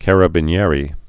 (kărə-bĭn-yârē, kärä-bē-nyĕrĕ)